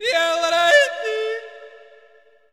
JODLER     2.wav